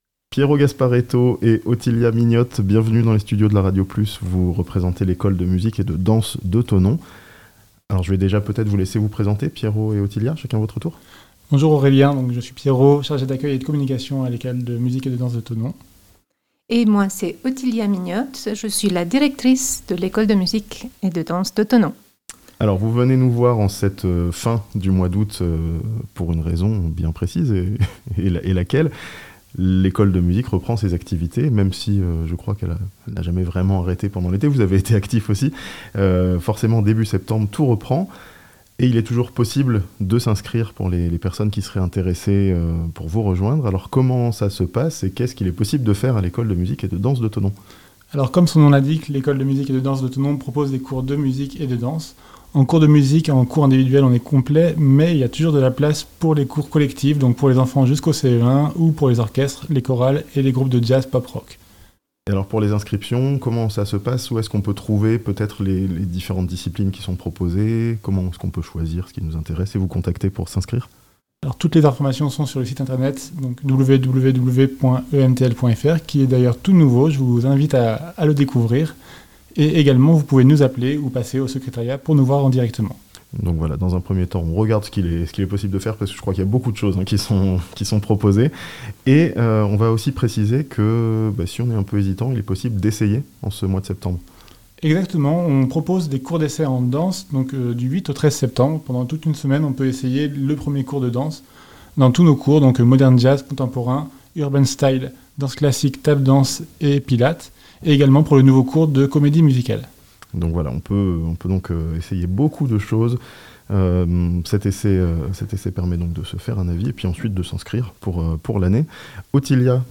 Les inscriptions sont ouvertes à l'école de musique et de danse de Thonon (interview)